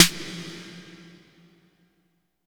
34 808V.SN-R.wav